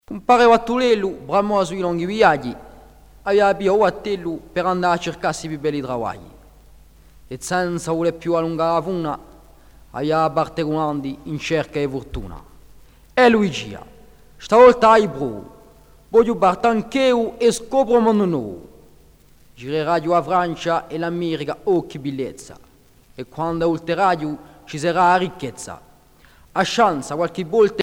Genre récit